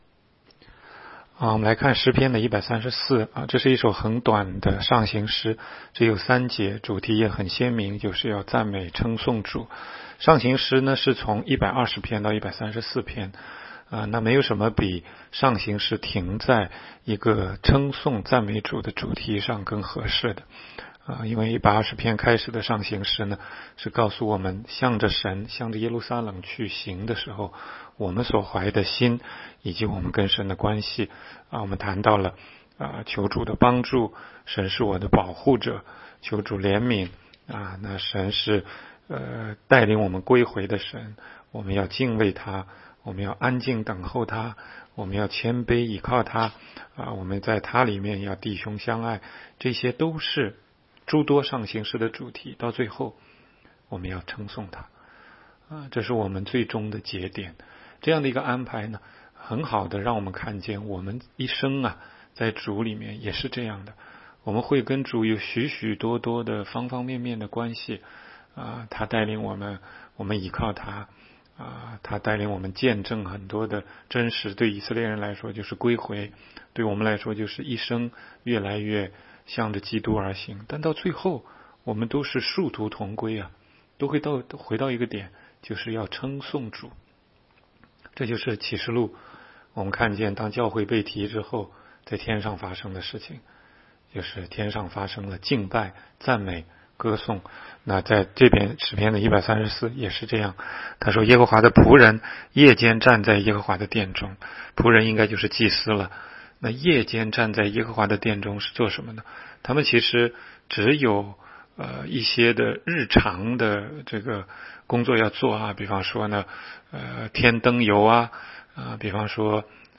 16街讲道录音 - 每日读经 -《 诗篇》134章